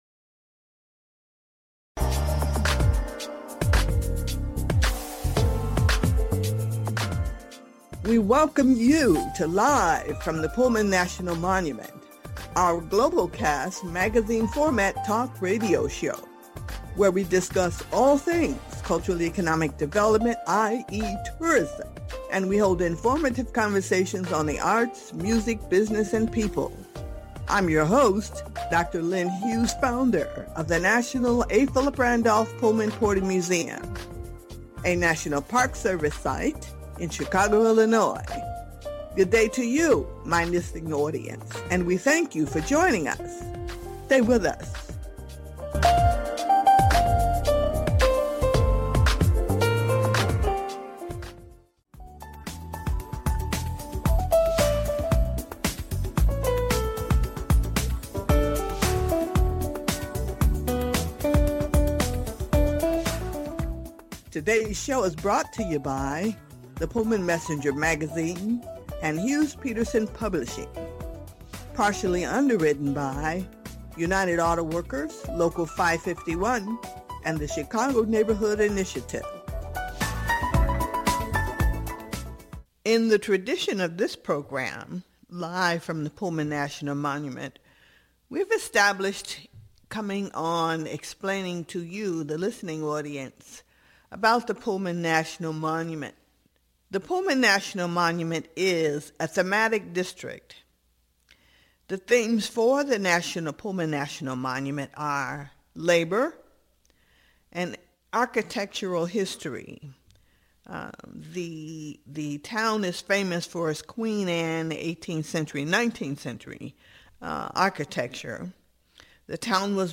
Talk Show Episode
A Rerun from February 26, 2017 CHOOSE CHICAGO, with Live introduction